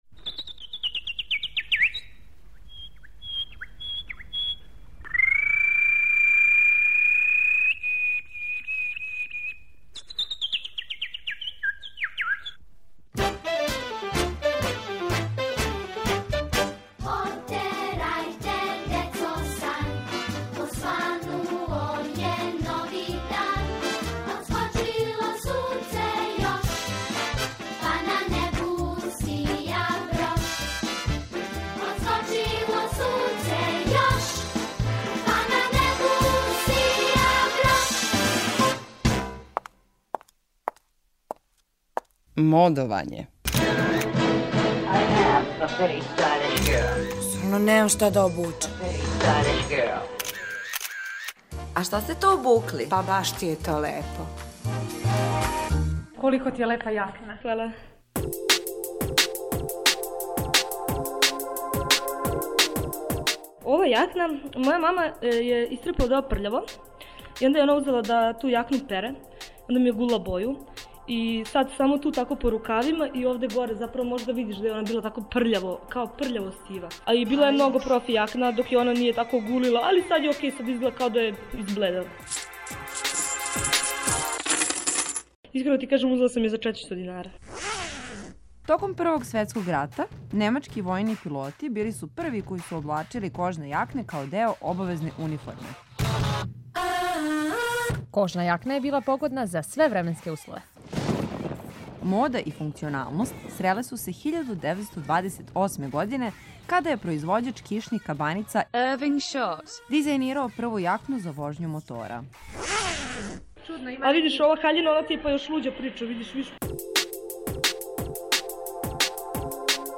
У серијалу Модовање питамо занимљиво одевене случајне пролазнике да опишу своју одевну комбинацију.